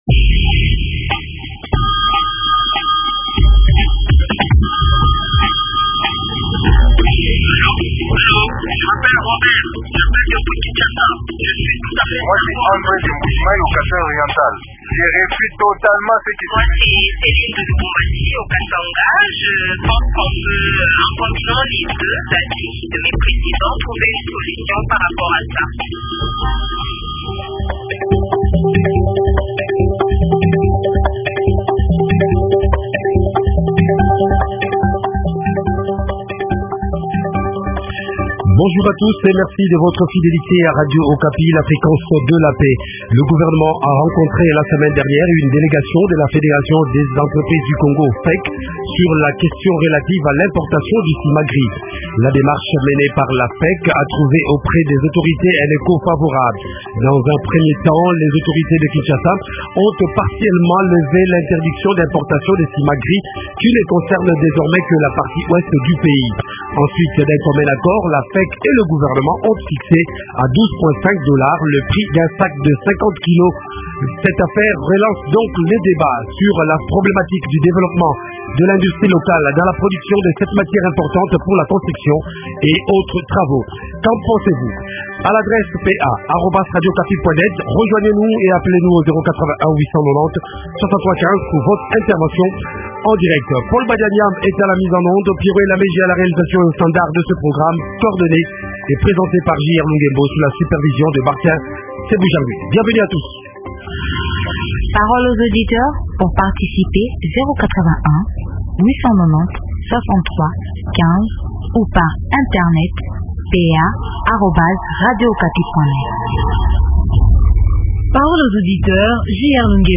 Invité: Jean Bamanisa, Gouverneur honoraire et député national honoraire, opérateur économique.